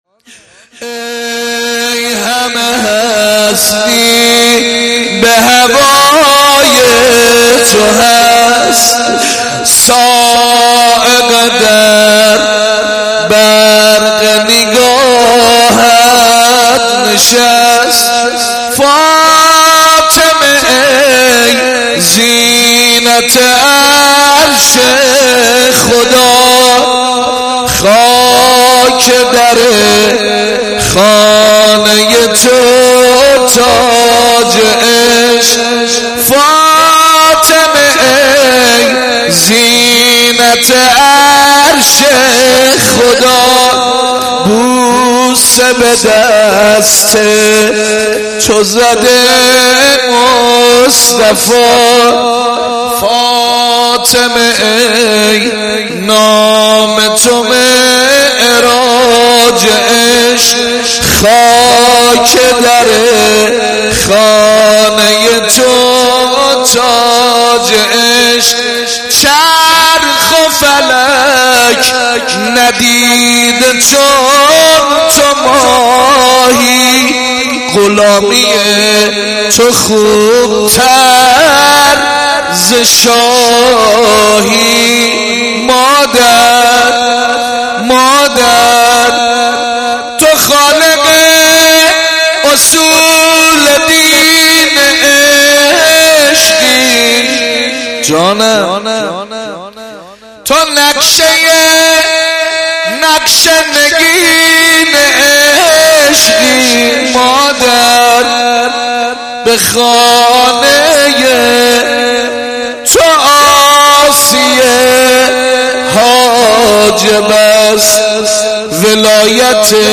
روضه محزون حضرت زهرا(س) با نوای جانباز و مداح اهل بیت(ع)